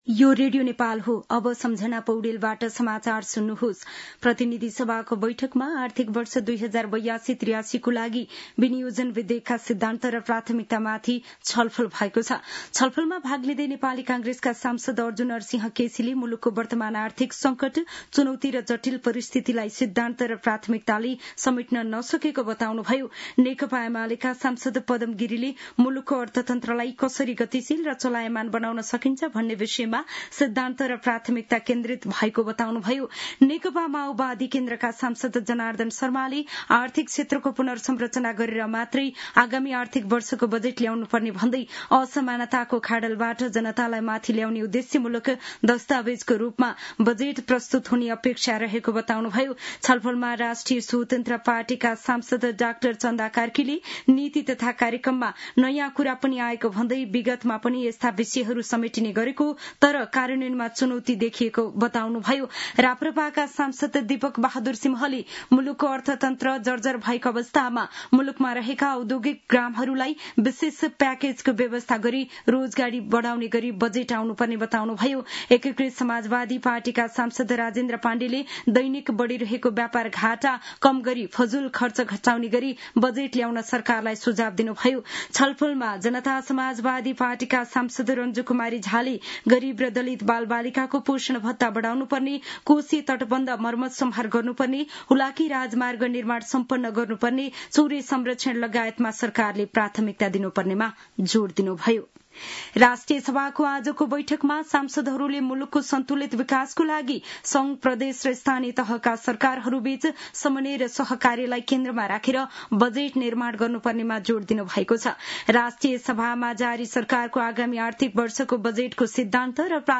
दिउँसो ४ बजेको नेपाली समाचार : ३१ वैशाख , २०८२
4-pm-news.mp3